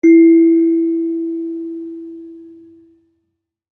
kalimba1_circleskin-E3-pp.wav